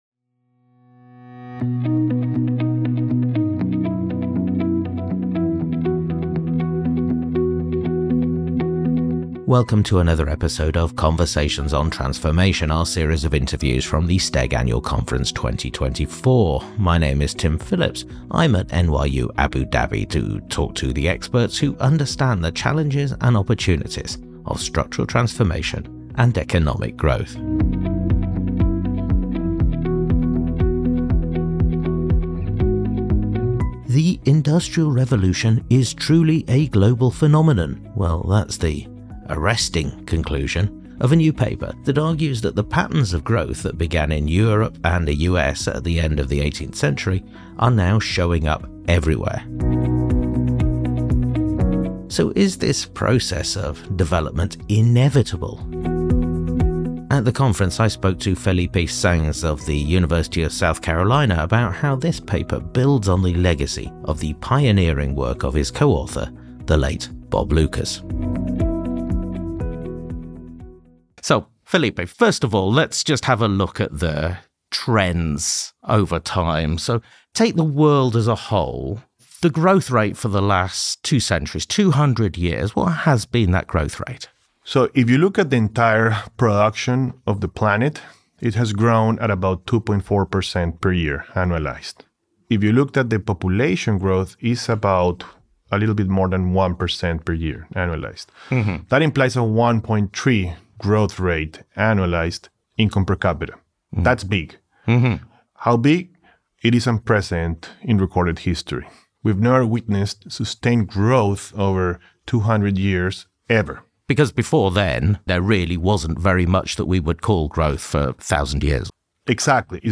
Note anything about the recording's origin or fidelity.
recorded at the STEG Annual Conference at NYU Abu-Dhabi